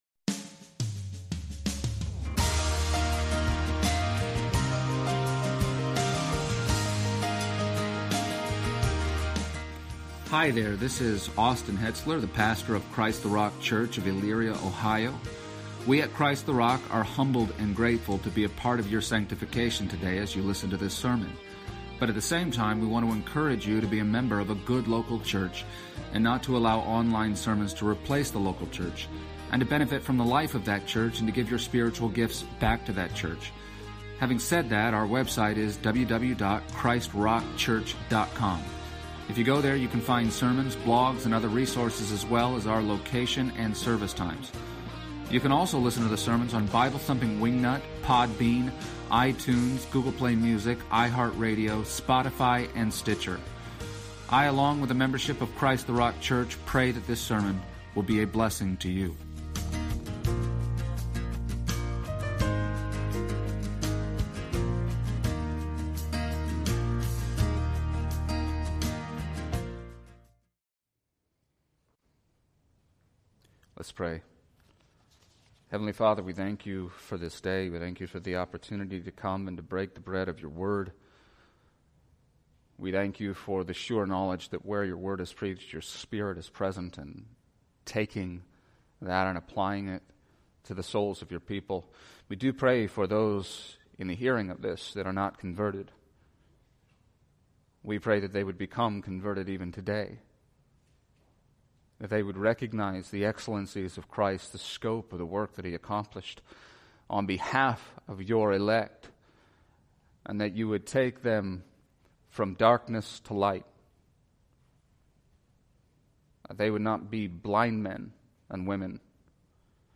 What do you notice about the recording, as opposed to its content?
Exposition of the Gospel of John Passage: John 19:17-30 Service Type: Sunday Morning %todo_render% « It Is Finished It Is Finished